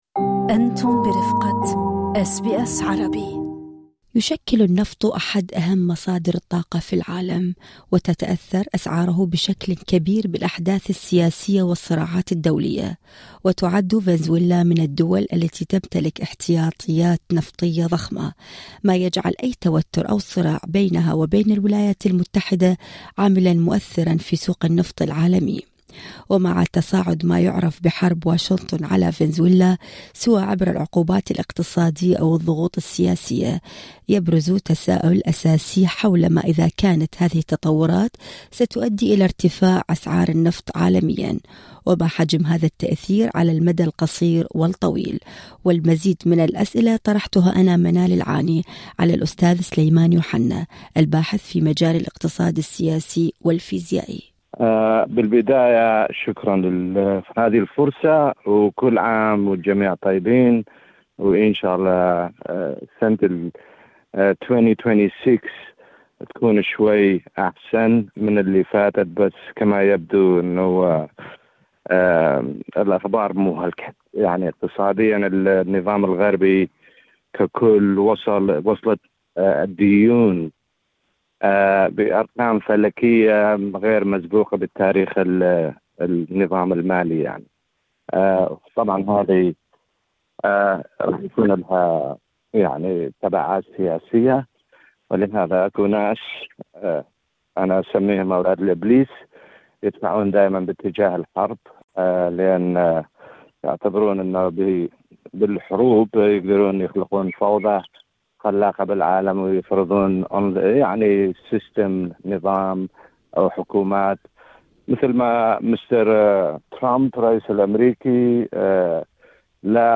في لقاءٍ تستمعون له في التدوين الصوتي اعلاه